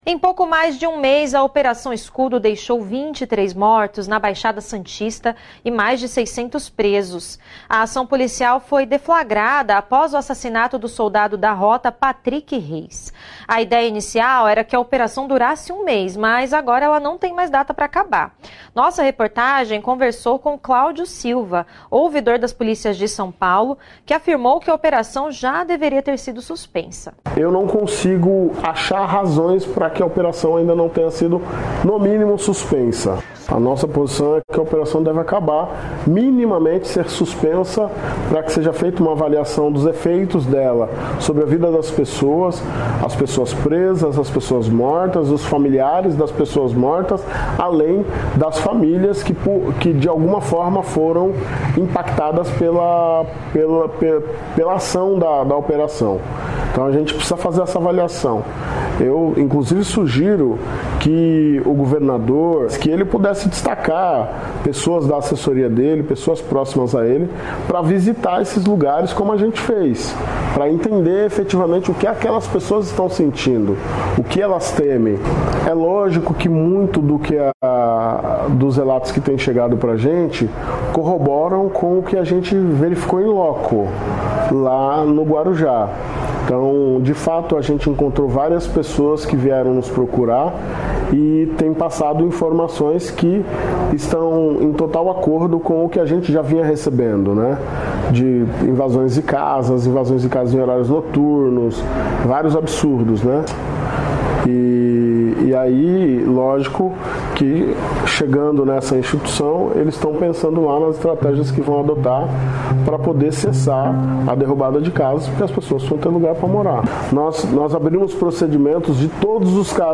O governo do estado de São Paulo deveria interromper imediatamente a Operação Escudo, disse Claudio Silva, ouvidor das polícias de São Paulo (SP), nesta quarta-feira (30), ao Brasil de Fato, durante o programa Central do Brasil.